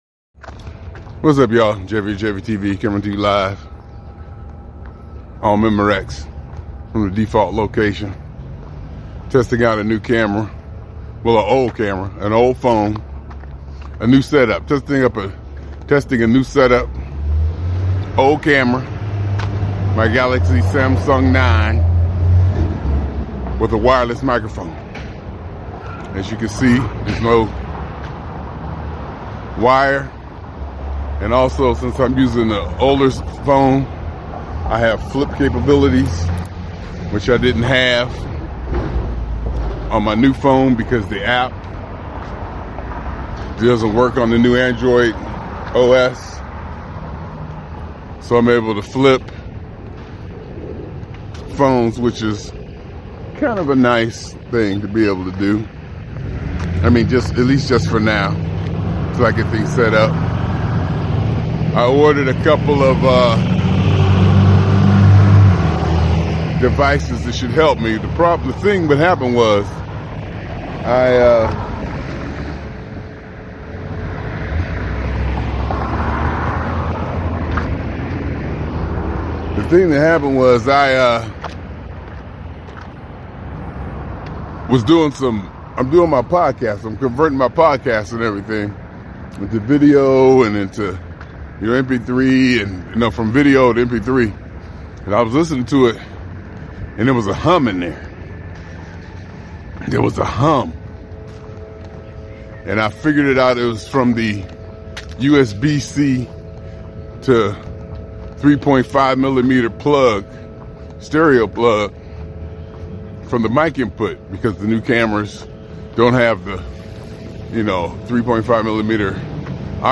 He mentions issues with a hum in recordings due to an adapter, which he addresses through noise reduction techniques.
Encountered audio hum issues due to a USBC to 3.5 mm adapter. Successfully implemented noise reduction techniques in post-processing.